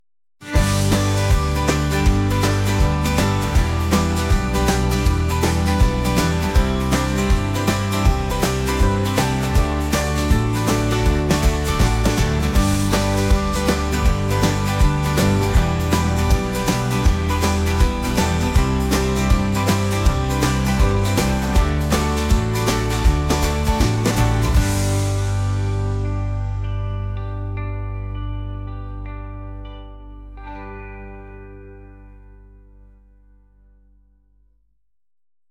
acoustic | pop | folk